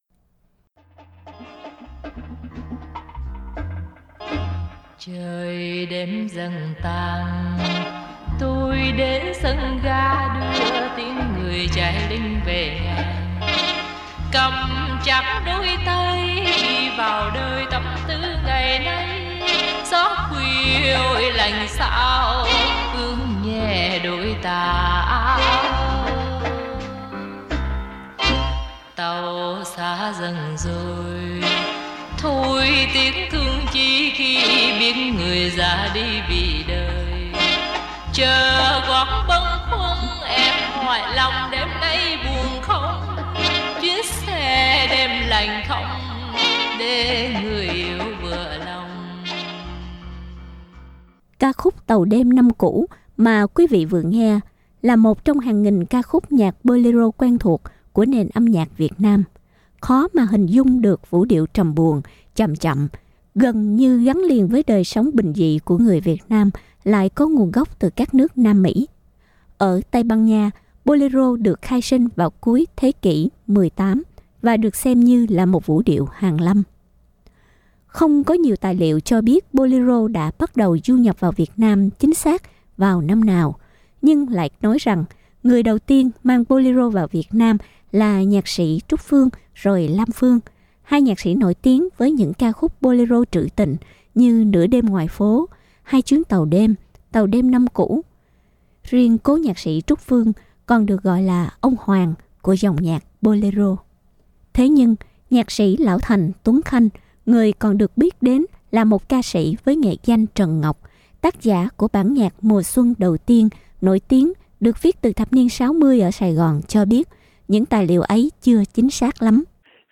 Để hiểu nhiều thêm về lịch sử cũng như những đặc điểm của thể loại này, mời quí vị cùng trò chuyện với nhạc sĩ lão thành Tuấn Khanh, nữ danh ca Thanh Thuý và nghe lại những tác phẩm Bolero nổi tiếng.